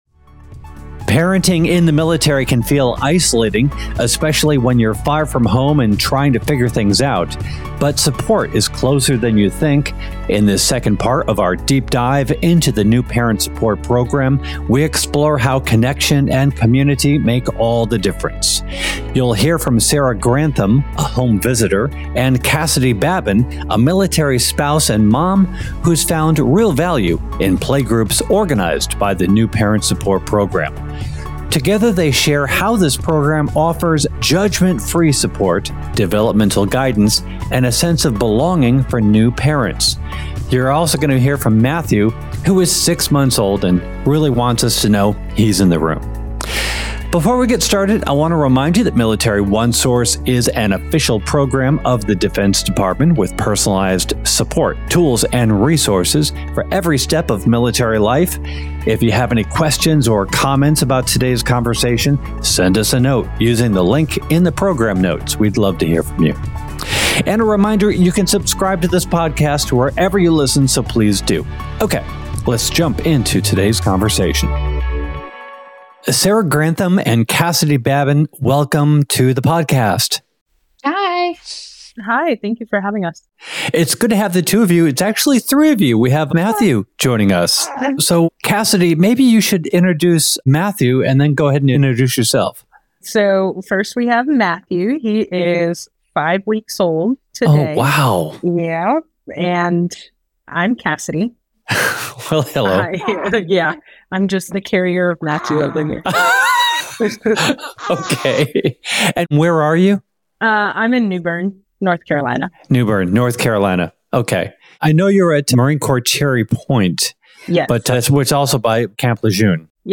This episode continues our deep dive into the New Parent Support Program, featuring insights from a Marine Corps spouse and her home visitor.